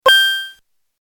Retro Game Item Collect Sound Effect
Description: Retro game item collect sound effect. Chiptune tone similar to older games like Mario or other classic platformers and arcade games. Classic arcade item pickup, NES-style collect sound.
Retro-game-item-collect-sound-effect.mp3